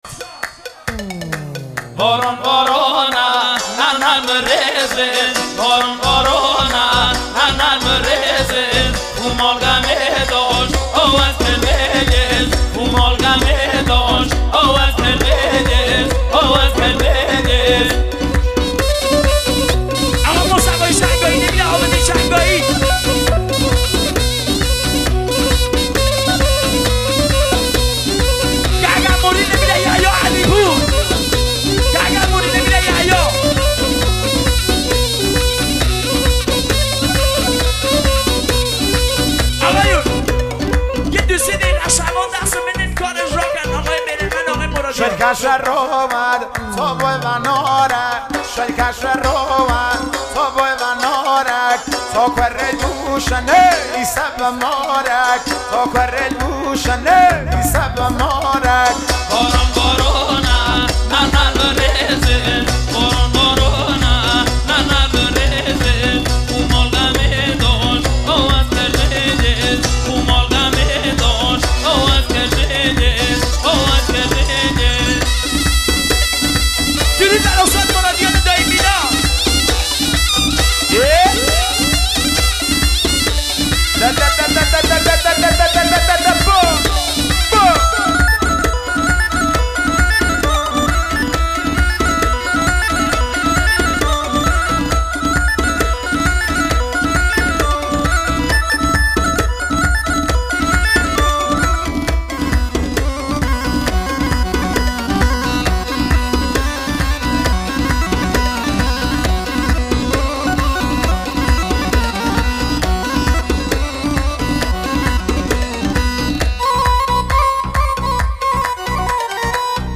اهنگ شاد لکی و لری با ارگ